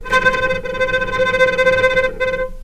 healing-soundscapes/Sound Banks/HSS_OP_Pack/Strings/cello/tremolo/vc_trm-C5-pp.aif at b3491bb4d8ce6d21e289ff40adc3c6f654cc89a0
vc_trm-C5-pp.aif